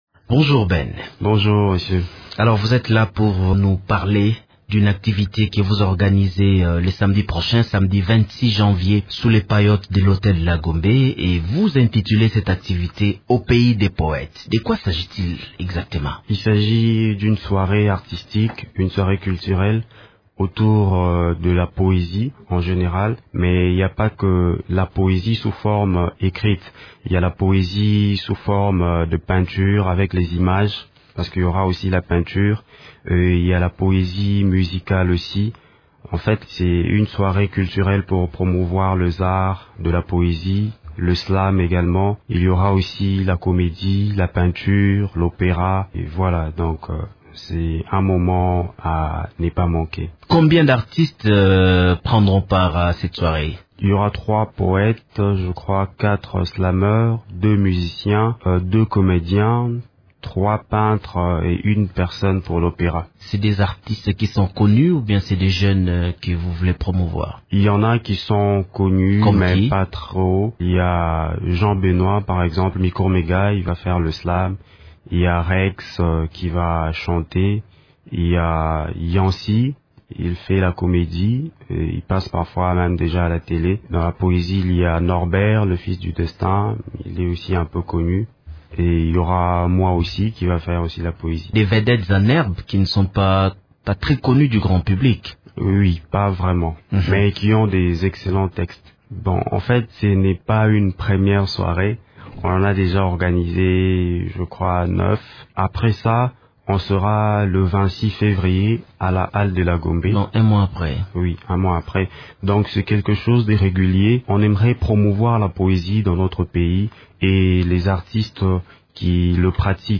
est interrogé par